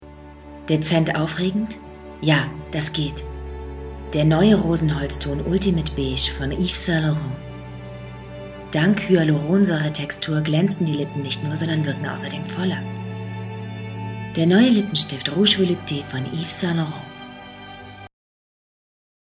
Sprechprobe: Industrie (Muttersprache):
Actress, Dubbing, Advertisement, Games, Audio Drama, Voice-Over, Native Speaker (German), English (US), Warm, Feminine, Sensitive, Clear, Laid-Back, Cool, Young, Fresh, Hip, Charming, Seductive, Provoking, Challenging, Dark, Mad, Angry, Wicked, Emotional, Understatement, Comical, Funny, Comic-Voice, Playful